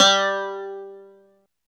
27 CLAV G3-L.wav